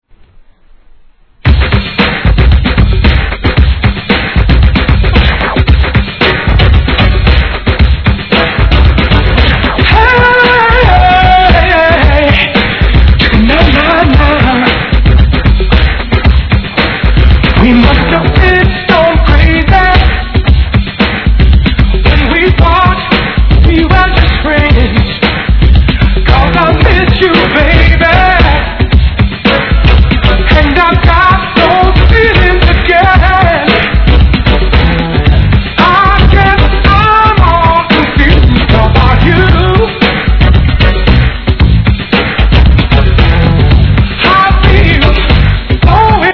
HIP HOP/R&B
'90年代初頭大ヒットのポップダンス!!